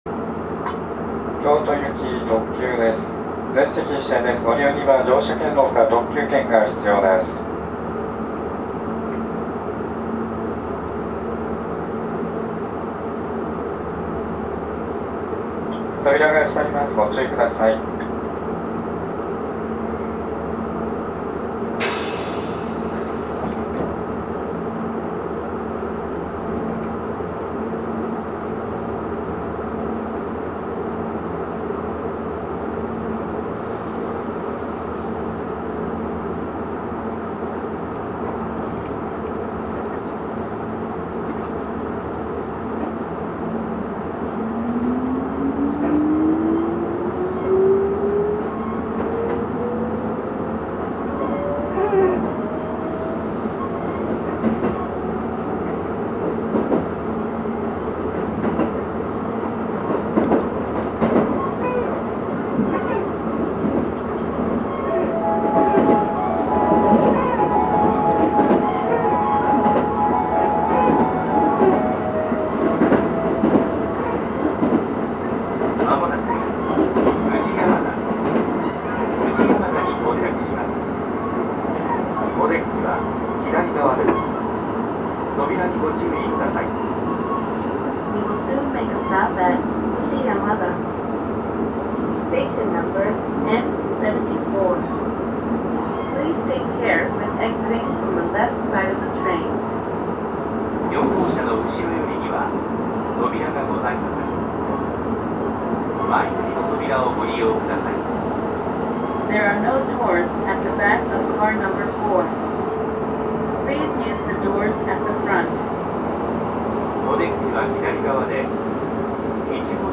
走行機器は抵抗制御で定格180kWのMB-3127-Aを制御します。
走行音
録音区間：五十鈴川～宇治山田(お持ち帰り)